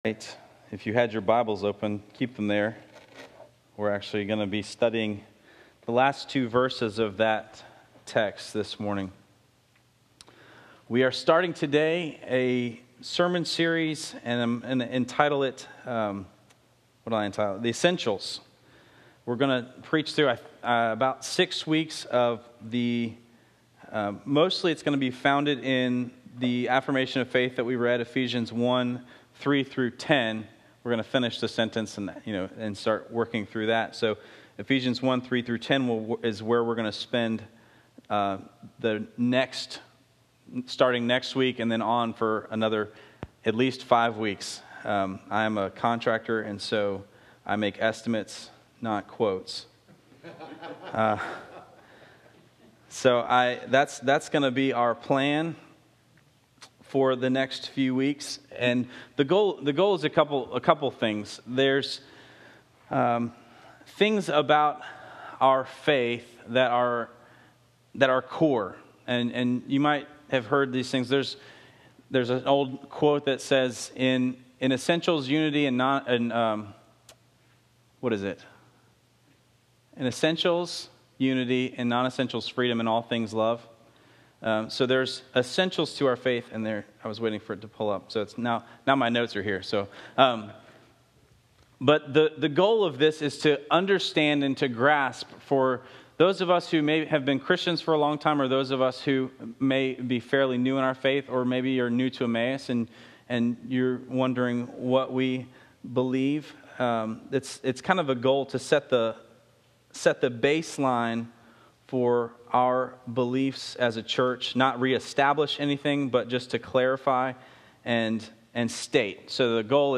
I want this to be a sermon, not a lecture.